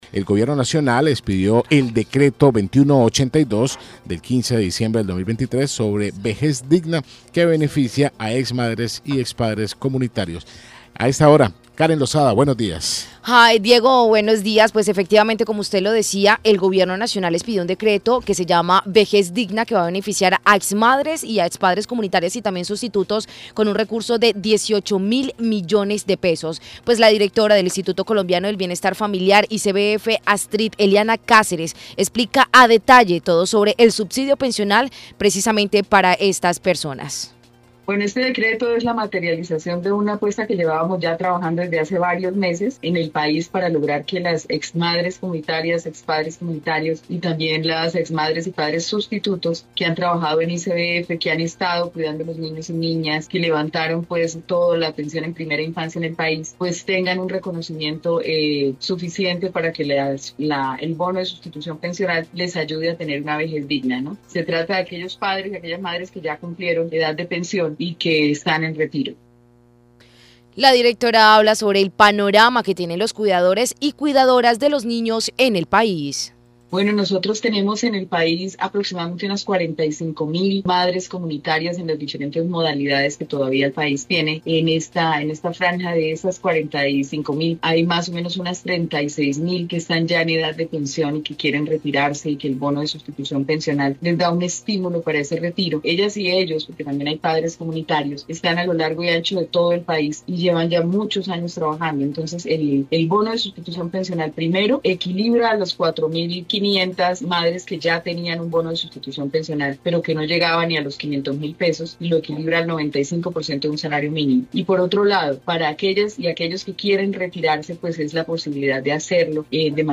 Astrid Eliana Cáceres, directora del Instituto Colombiano de Bienestar Familiar (ICBF), detalla los subsidios de pensiones para ex madres y ex padres comunitarios y sustitutos.
La directora habla sobre el panorama que tienen los cuidadores y cuidadoras de los niños en el país.